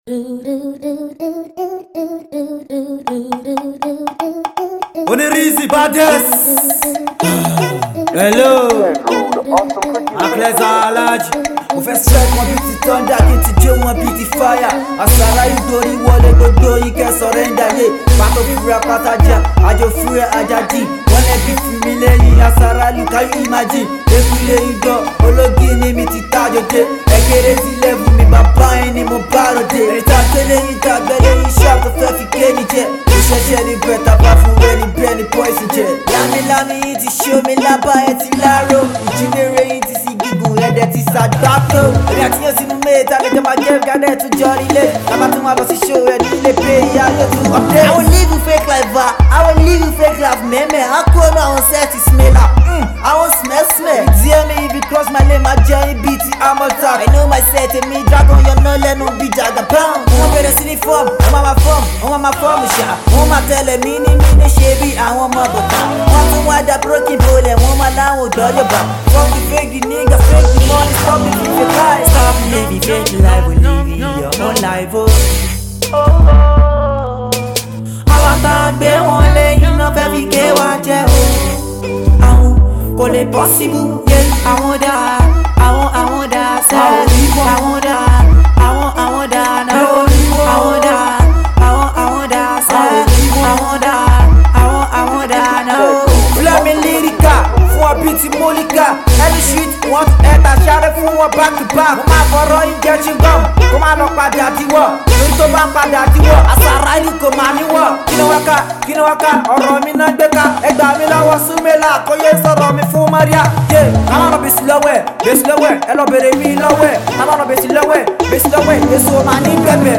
Pure rap